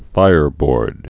(fīrbôrd)